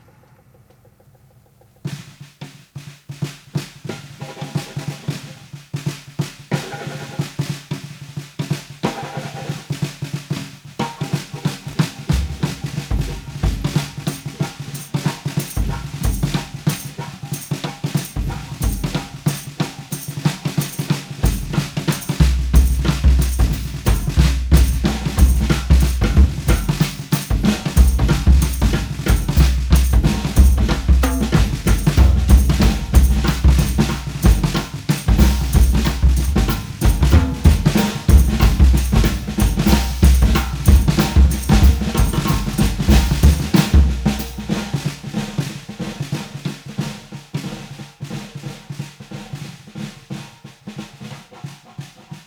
Blues Soul › BluesRock